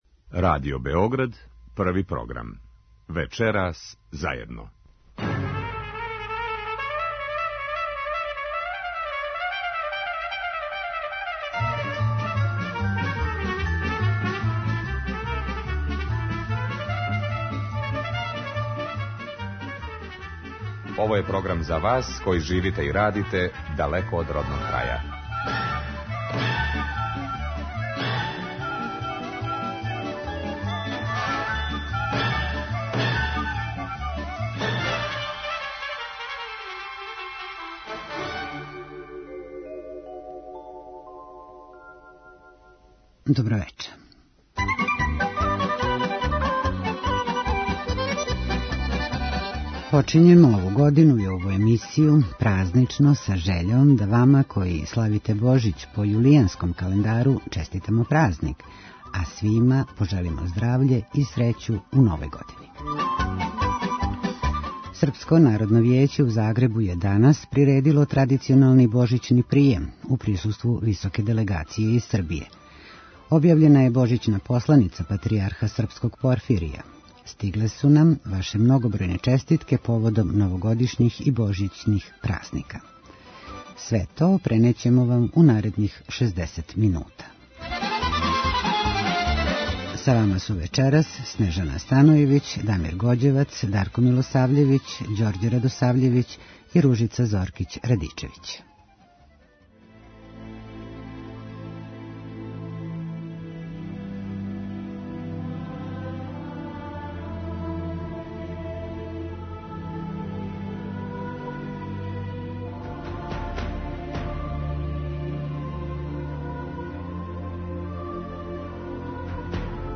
Чућете и неке од честитки које су стигле у нашу редакцију.